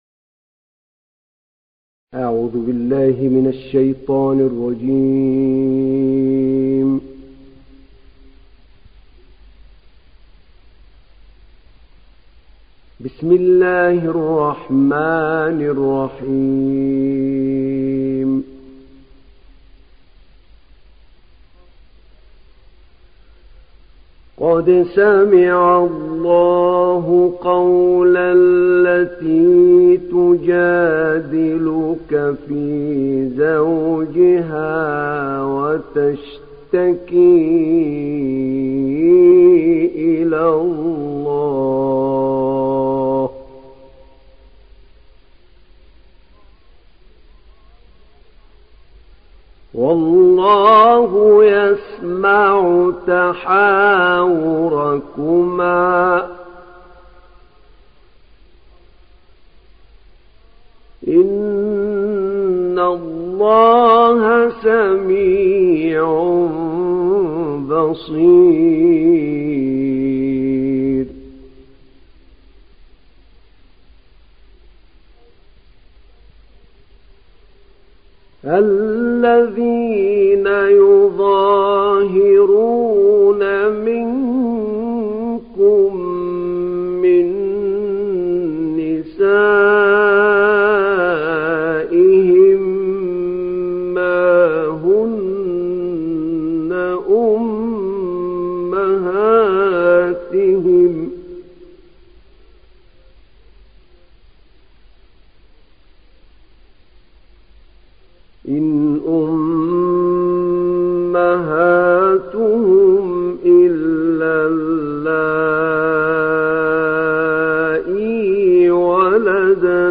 Mücadele Suresi İndir mp3 Ahmed Naina Riwayat Hafs an Asim, Kurani indirin ve mp3 tam doğrudan bağlantılar dinle